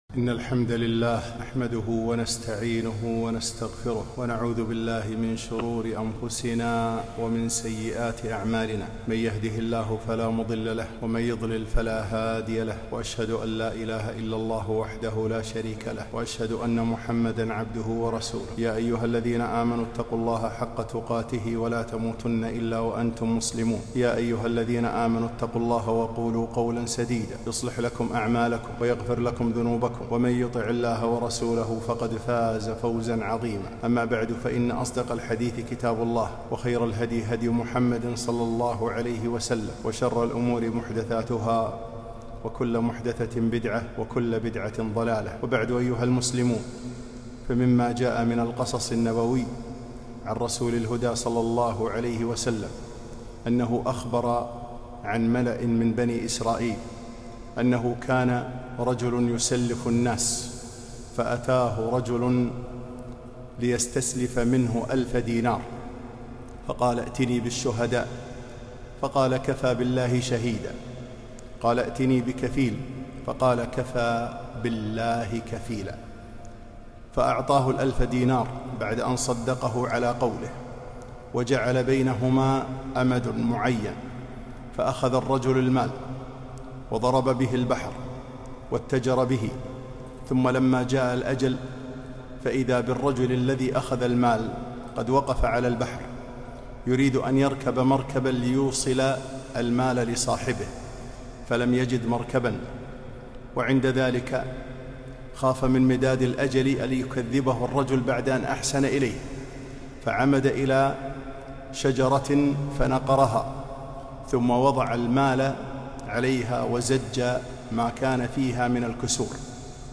خطبة - التعلق بالله وأداء حقوق الخلق